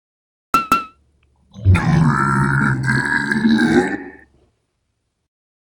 fire-3.ogg